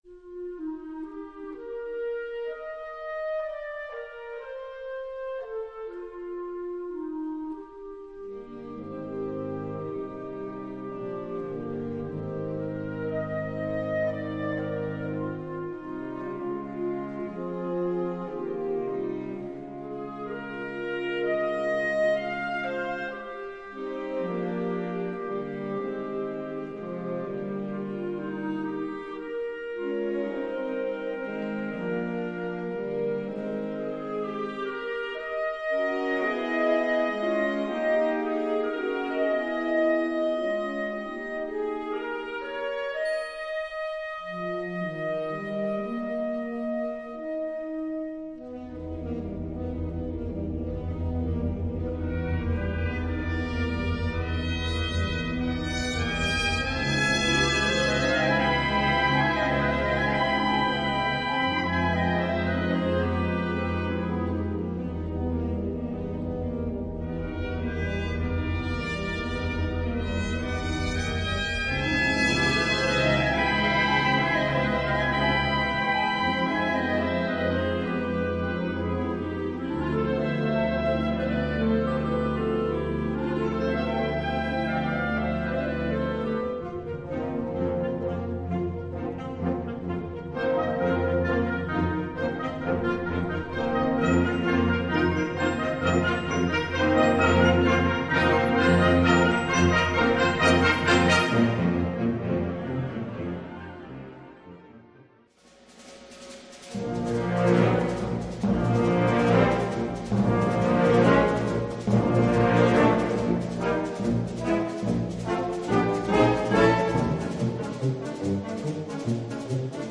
Besetzung: Blasorchester
Eine außergewöhnliche, prickelnde Komposition.